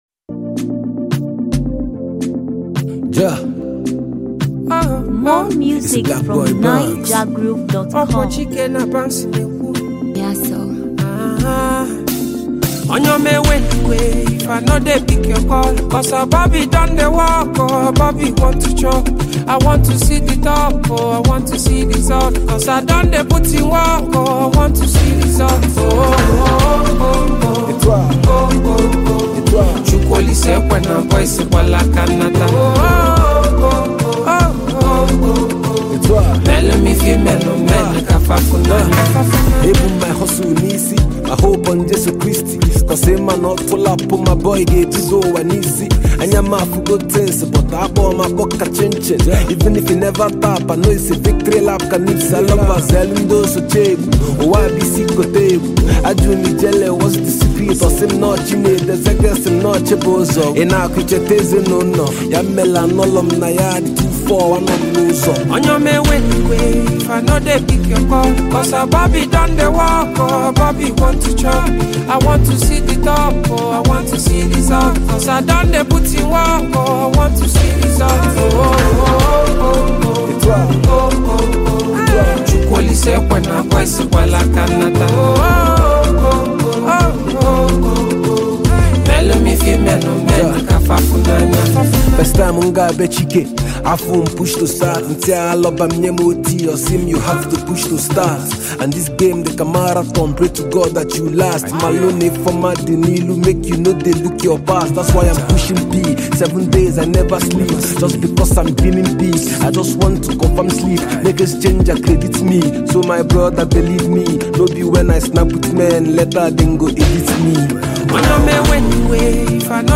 Latest, Naija-music, African-music